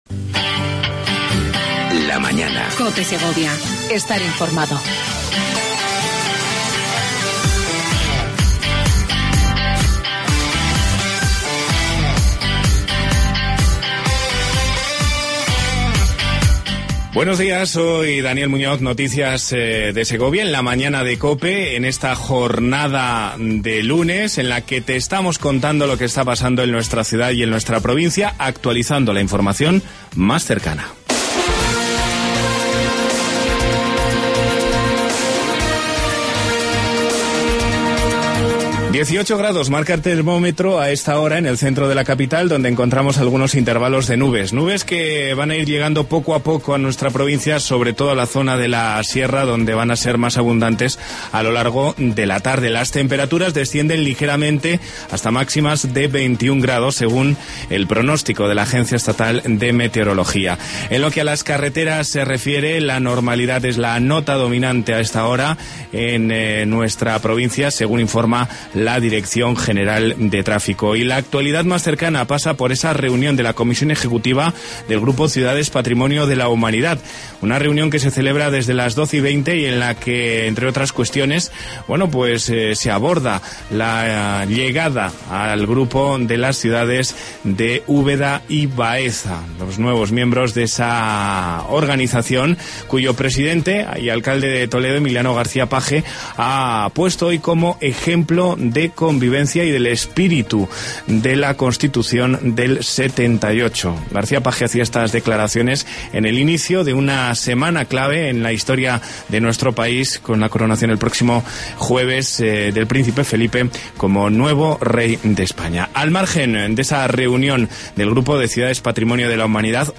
AUDIO: Entrevista con Pilar Sanz, Subdelegada de Gobierno en Segovia.